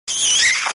Kiss2.wav